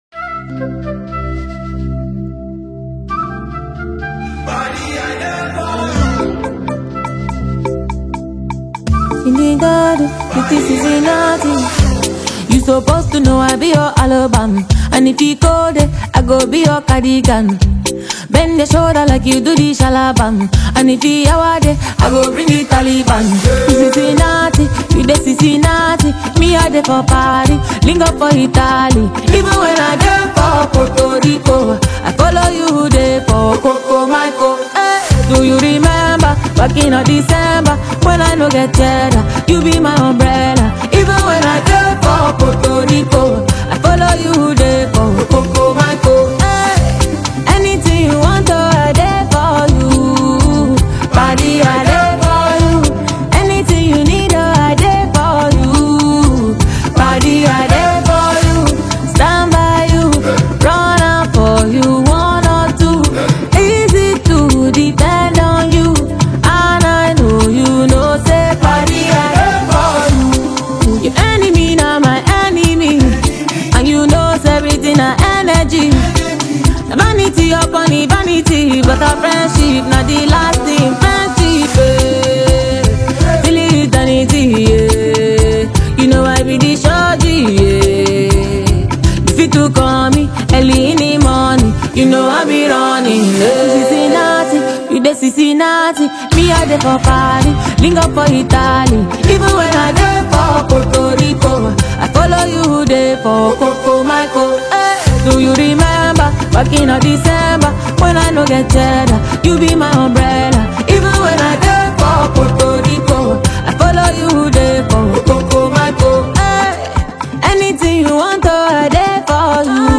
Afropop